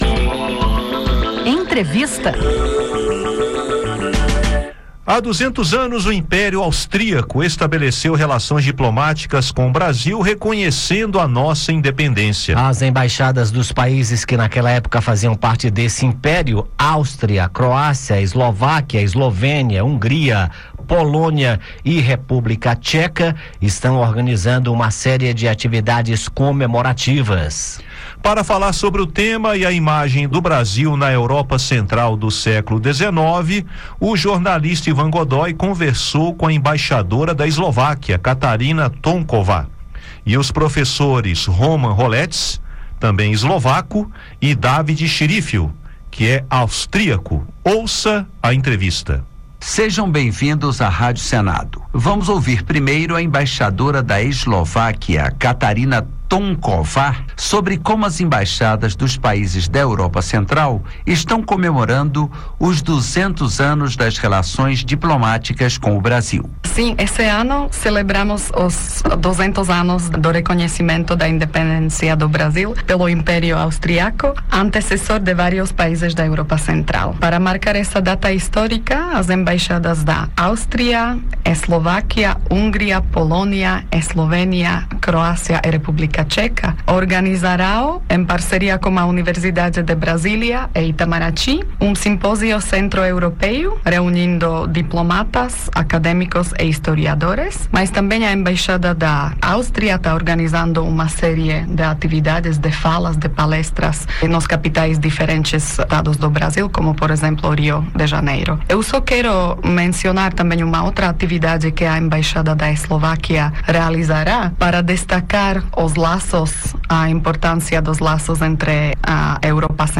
conversou com a embaixadora da Eslováquia